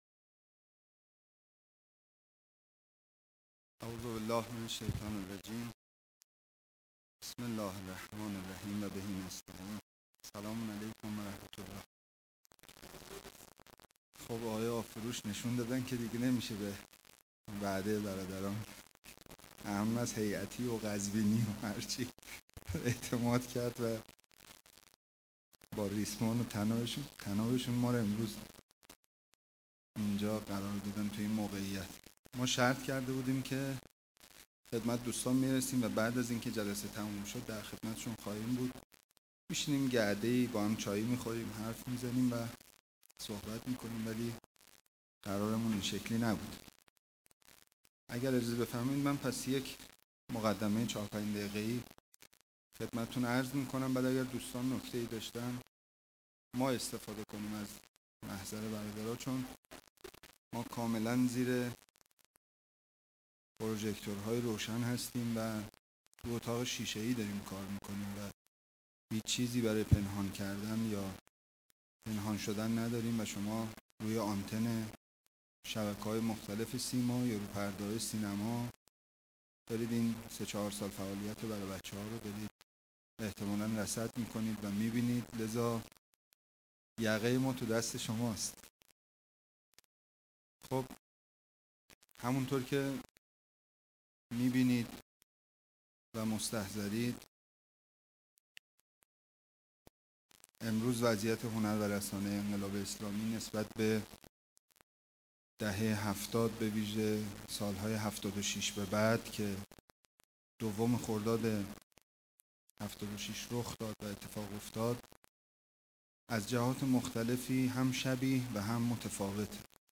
سخنرانی
در نخستین اجلاس ملی رابطان جامعه ایمانی مشعر